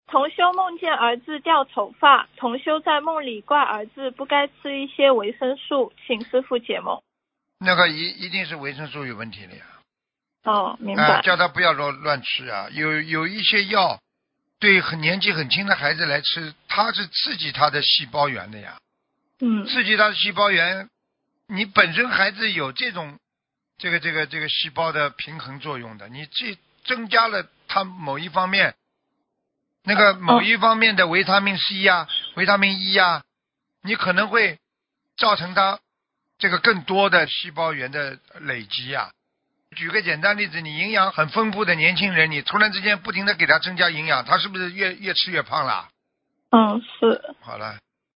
2. 录音文字整理尽量保持与原录音一致。但因对话交流带有语气，文字整理不可能完全还原情境。